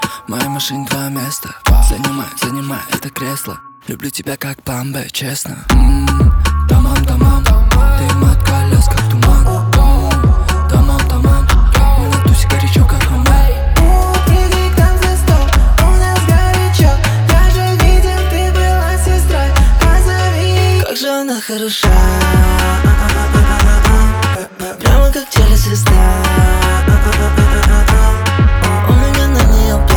Baile Funk Brazilian